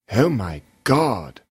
Звуки удивления, восторга
Звук мужского голоса, произносящего Oh my God